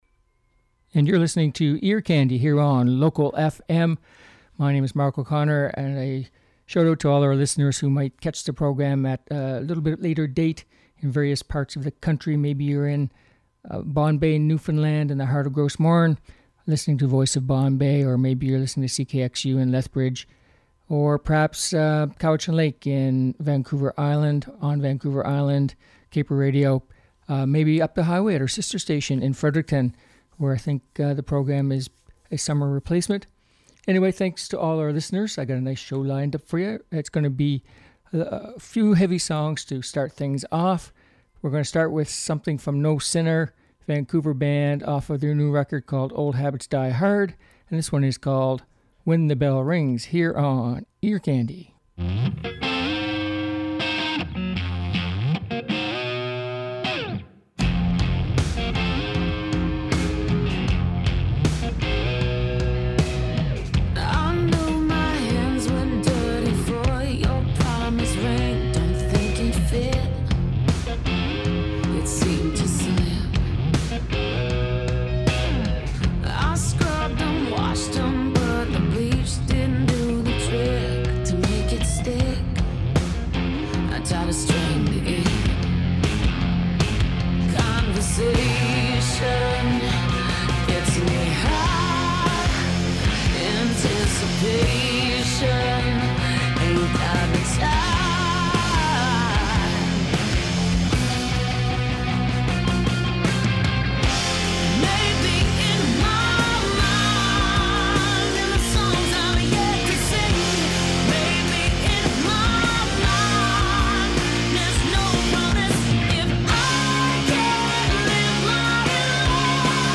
Uptempo Rock and Pop Songs